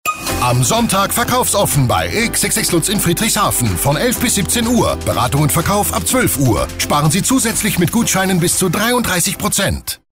Reminder-Spot